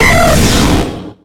Cri de Raikou dans Pokémon X et Y.